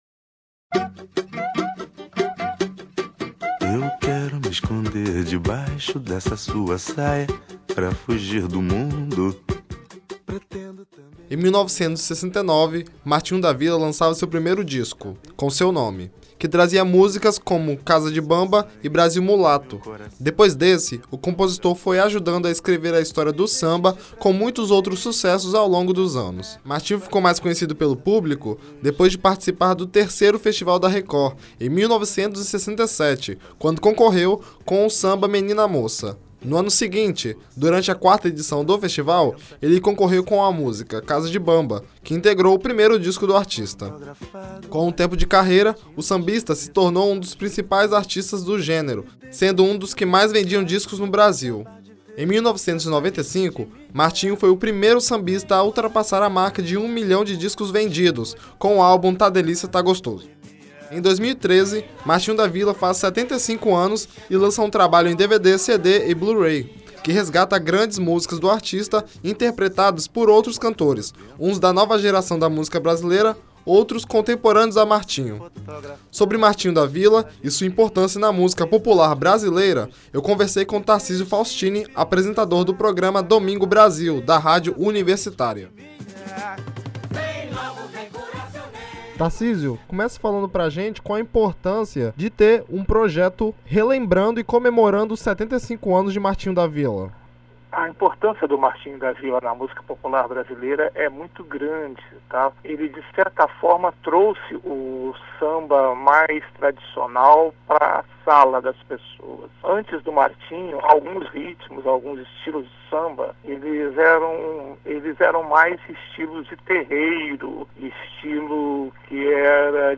Ouça a matéria aqui. 75 ano de Martinho da Vila Download : 75 ano de Martinho da Vila